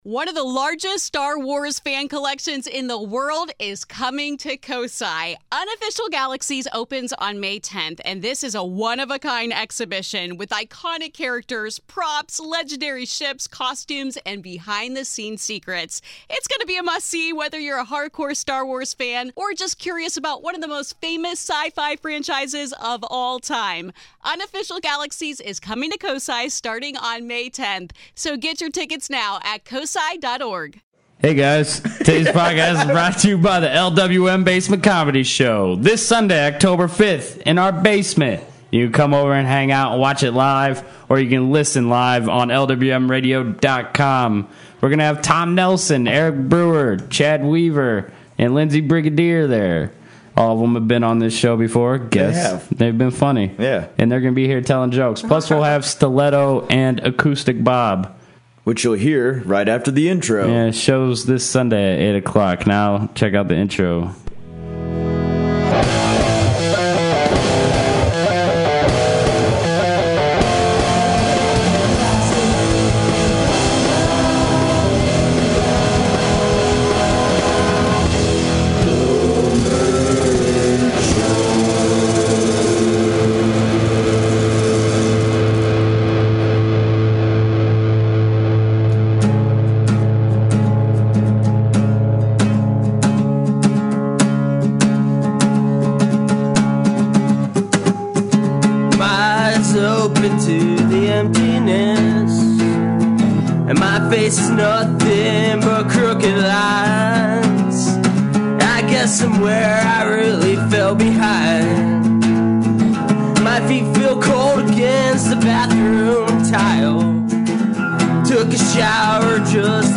Recording from studio dungeon this week